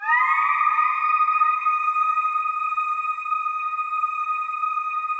WAIL RIP 9.wav